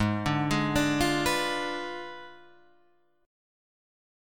G#dim7 chord {4 2 3 1 3 1} chord